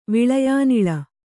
♪ viḷayānila